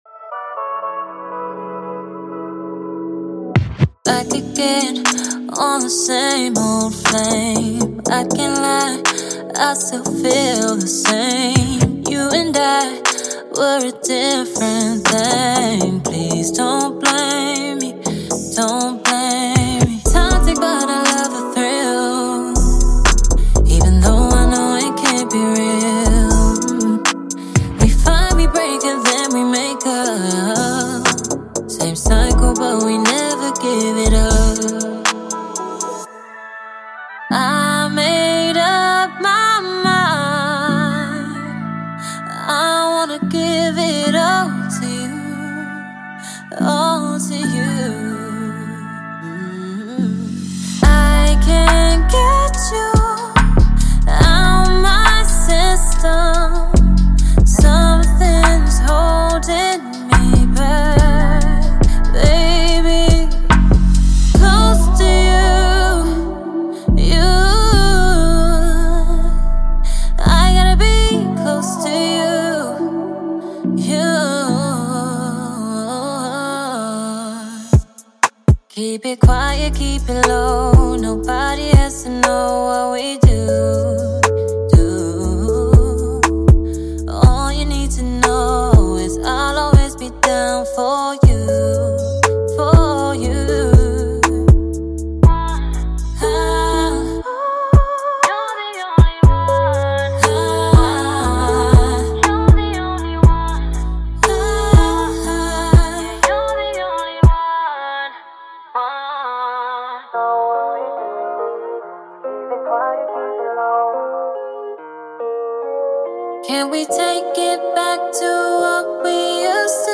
2026-01-03 R&B 216 推广
重新采样和环境人声循环旨在为您的节拍创造全新的质感、层次和亮点。
演示歌曲中包含的所有其他声音仅用于演示目的